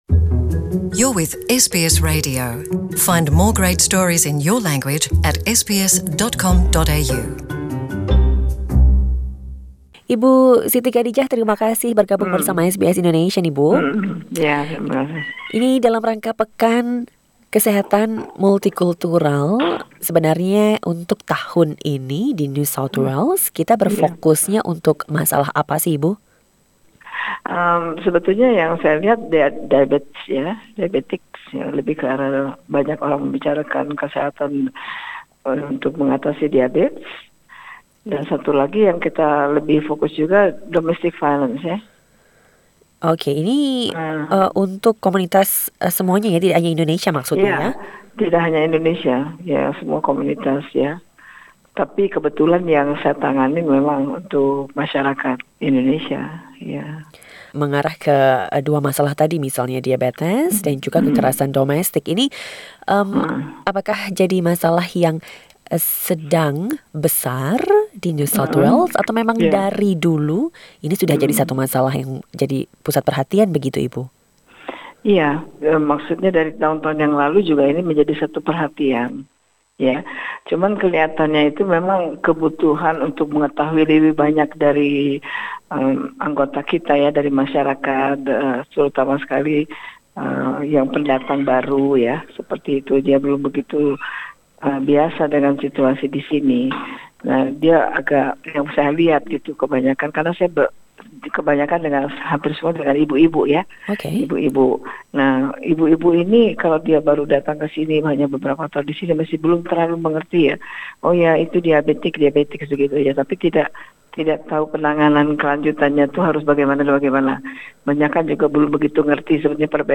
Even though Indonesian is not a new community in New South Wales, it is seen that there is a need to deliver the information on health in Bahasa Indonesia, especially for those new-settlers. SBS Indonesian interviewed